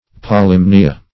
Polymnia \Po*lym"ni*a\, n.